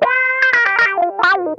ITCH LICK 9.wav